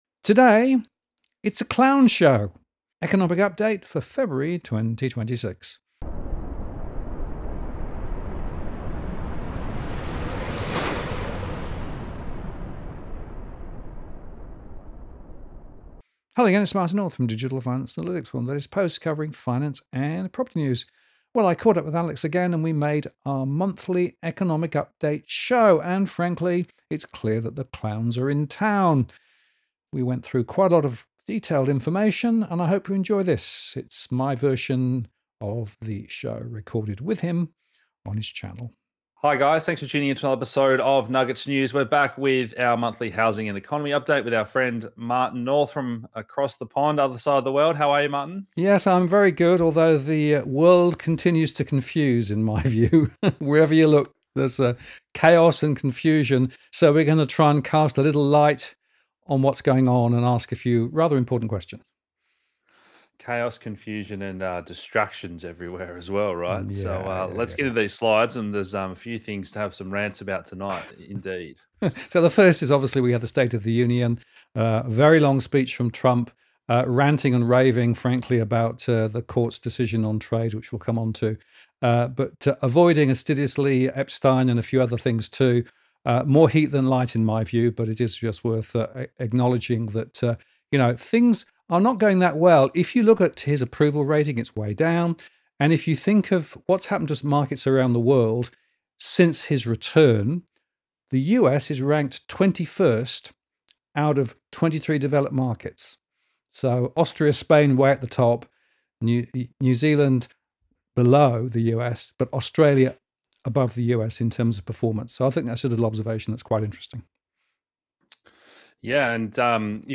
This is my edit our our monthly economic and housing update recorded with Nuggets News. In this edition we examine the State of the Union, AI, Market Dynamics, the Australian Economy, housing, and much much more.